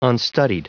Prononciation du mot unstudied en anglais (fichier audio)
Prononciation du mot : unstudied